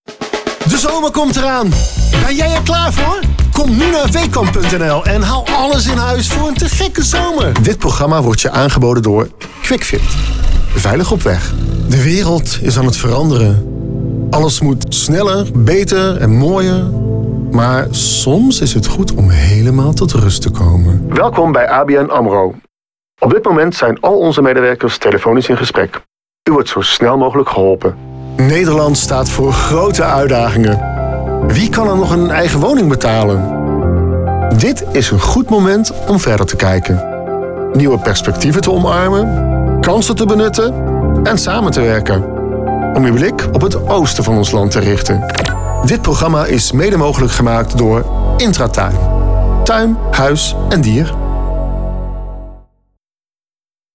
Een aantal voorbeelden van mijn voice-over opdrachten